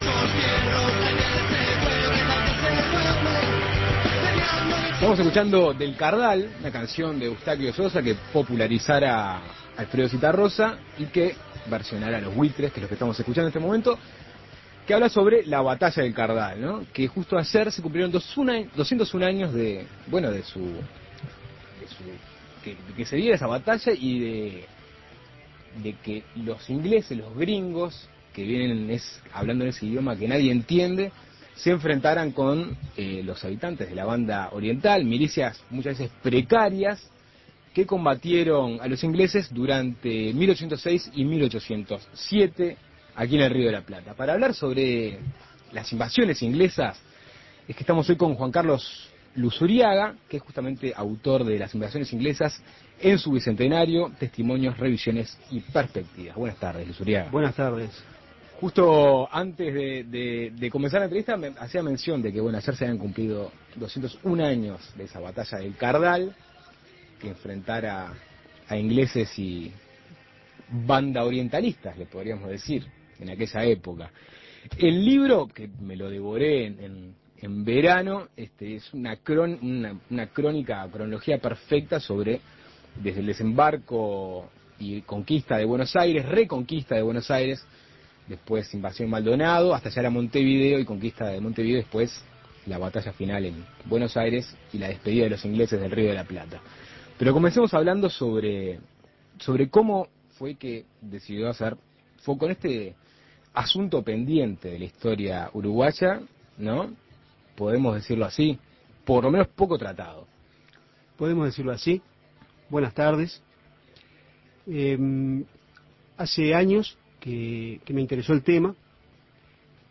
Entrevistas Historias de vida durante las invasiones inglesas Imprimir A- A A+ Este domingo se cumplieron 201 años de la Batalla del Cardal, que enfrentó a los ingleses con la Banda Oriental.